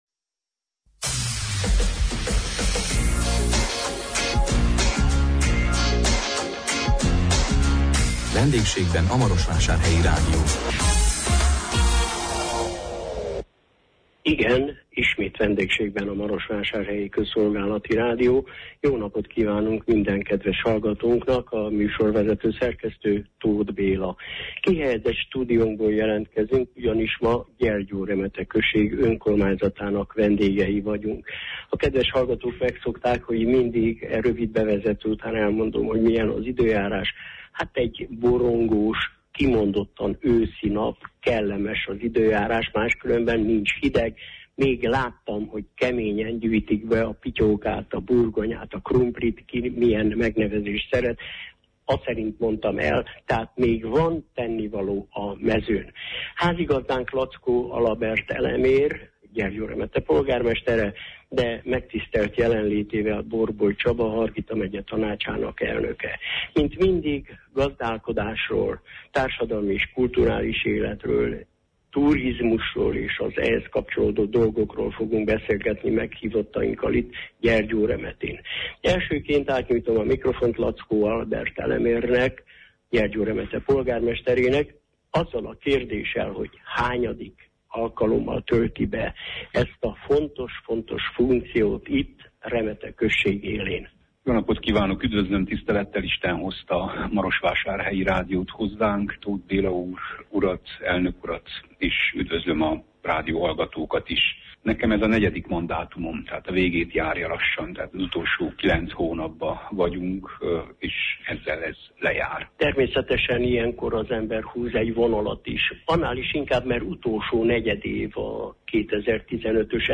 Meghívottak: László Albert Elemér a község polgármestere és Borboly Csaba a Hargita megyei tanács elnöke.